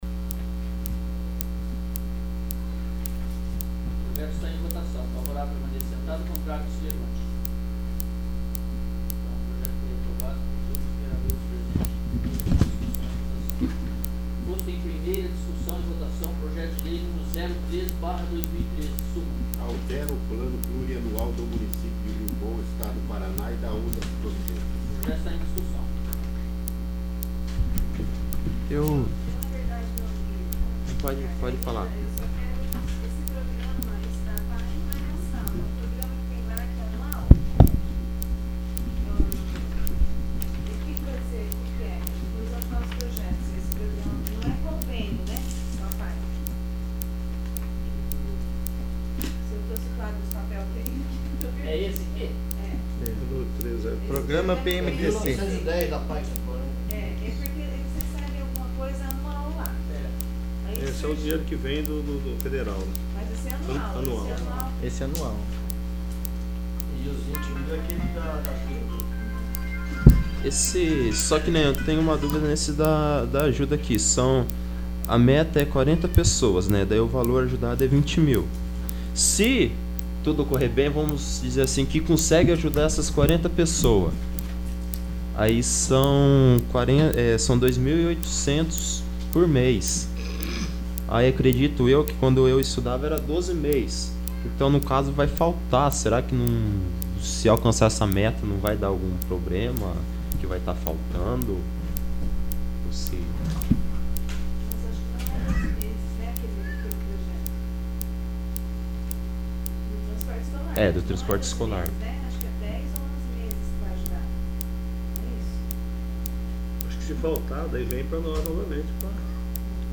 10º. Sessão Extraordinária